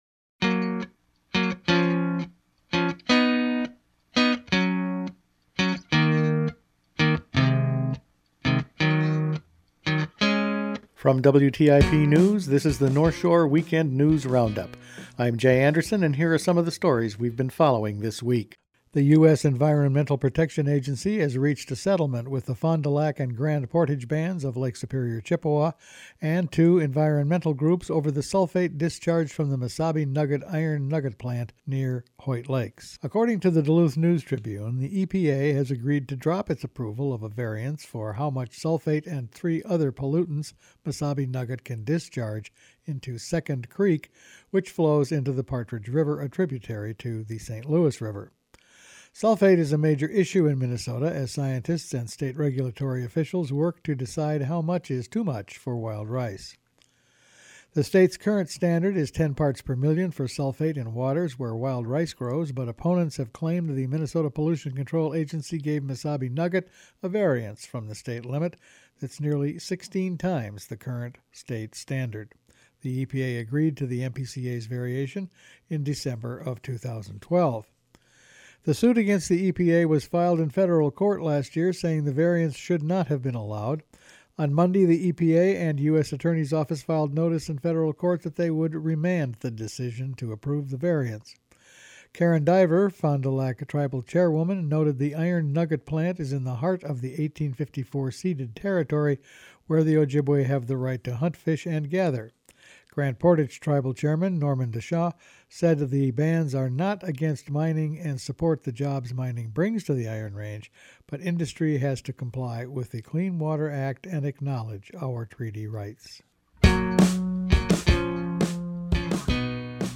Weekend News Roundup for March 15
Each week the WTIP news team puts together a roundup of the week's news. A new oil pipeline is proposed. The public, the EPA and DNR all weighed in on a couple of different mining projects. And Minnesota’s wolves get a slight reprieve.